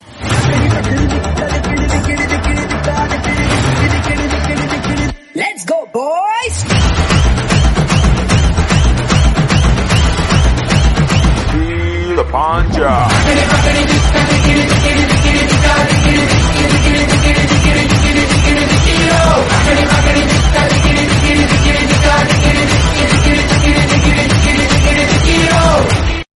energetic
party song ringtone